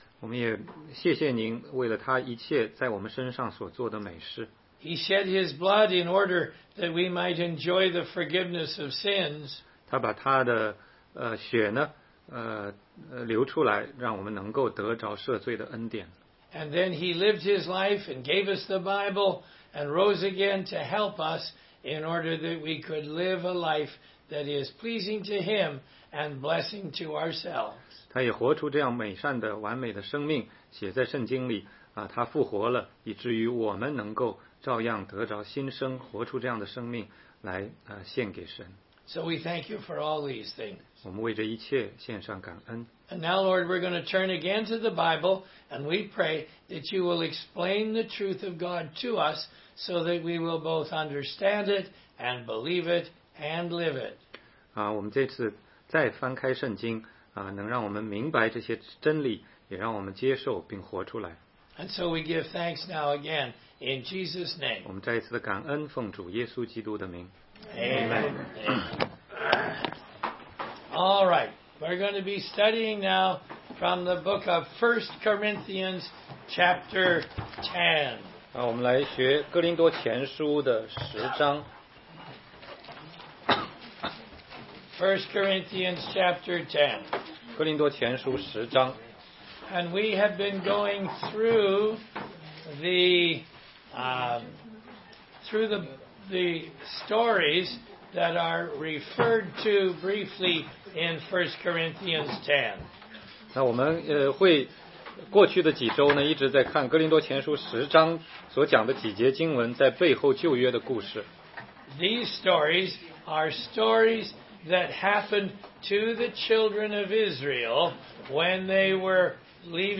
16街讲道录音 - 哥林多前书10章14节-11章1节：基督徒使用自由的原则